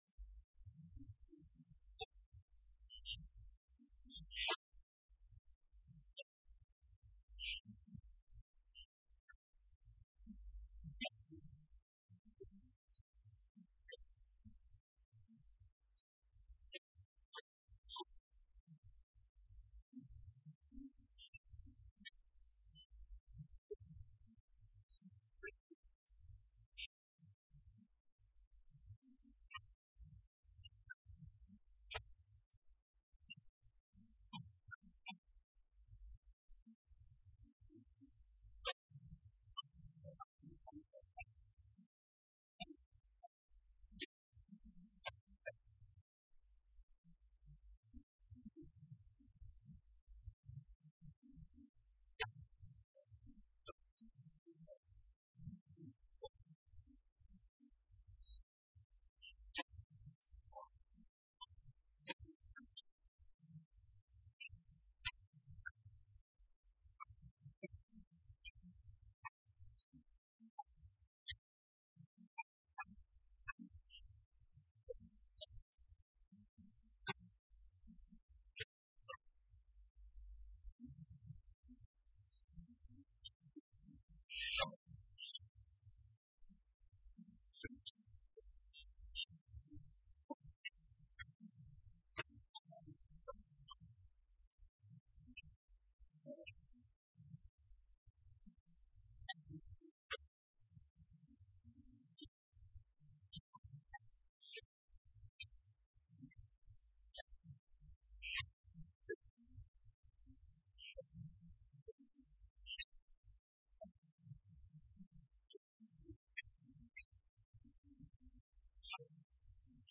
Vítor Fraga, numa comunicação à Assembleia Legislativa para apresentar as linhas gerais do Plano e entregar o documento à Presidente do Parlamento, salientou que o PIT está “focado no serviço a prestar ao cidadão e às empresas”.